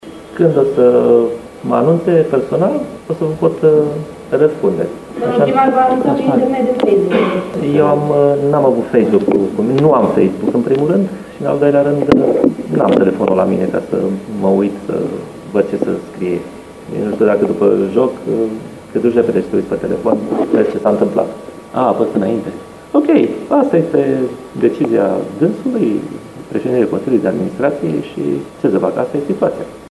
După 1-4, acasă, astăzi, cu FC Botoșani, Grigoraș a aflat despre demitere la conferința de presă de după meci.